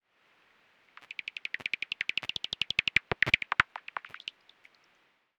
Paarverblijven van gewone dwergvleermuis
De ultrasone geluiden van de sociale roepjes verschillen in ritme en toonhoogte met die van de echolocatie en zijn daarom goed herkenbaar (zie de geluidsopnames hieronder).
Overvliegend mannetje gewone dwergvleermuis (Pipistrellus pipistrellus) in een woonwijk op 4 meter hoogte in de avondschemer in september . Echolocatie en sociale roep te horen.  Opnames zijn gemaakt met een Petterson D240x vleermuisdetector op 48 Khz
PipPip_48Khz_Sociaal.wav